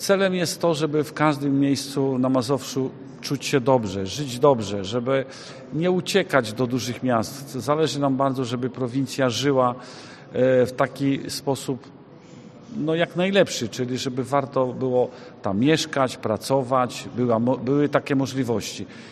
Dzięki realizowanym zadaniom, mieszkańcy nie muszą wyjeżdżać ze swoich miejscowości podsumowuje radny województwa mazowieckiego, Mirosław Augustyniak: